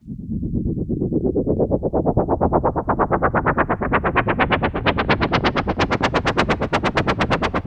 Index of /90_sSampleCDs/Classic_Chicago_House/FX Loops
cch_fx_chopper_125.wav